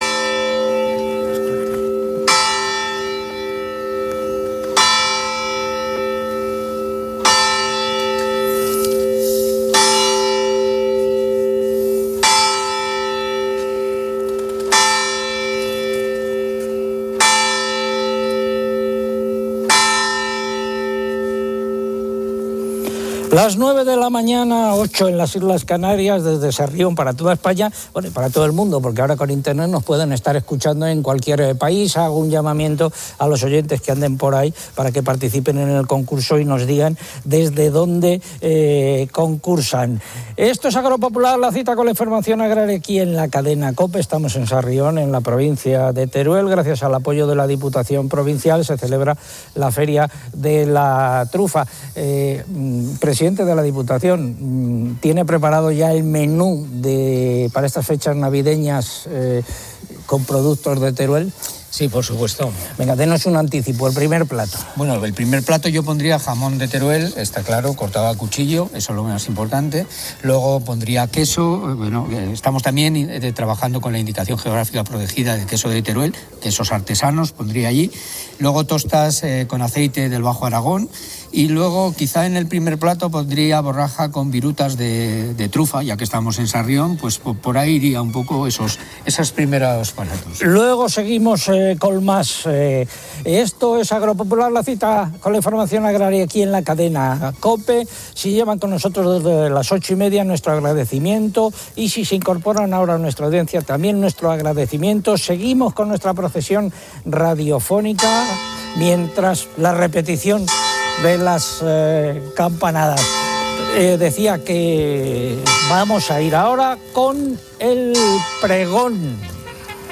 Agro Popular, desde la feria de la trufa en Sarrión, Teruel, informa que la Diputación propone un menú navideño con productos locales como jamón,...